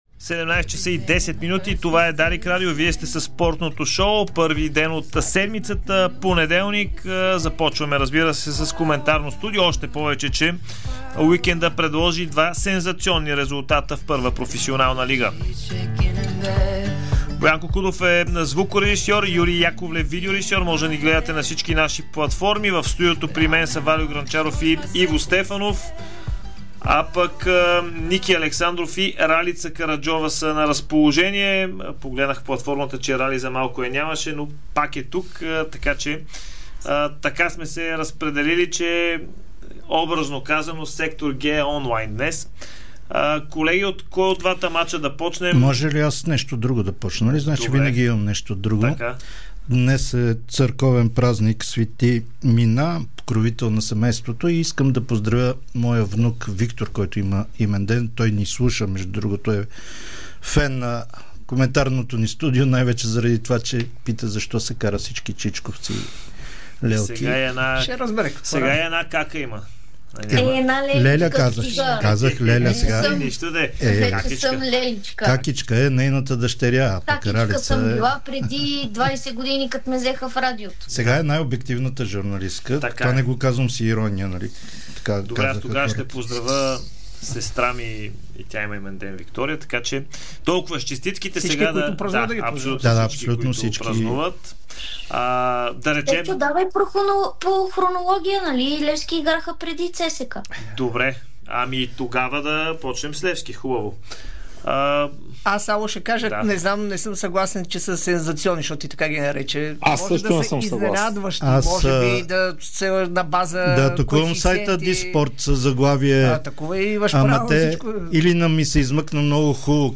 Коментарно студио след провалите на Левски и ЦСКА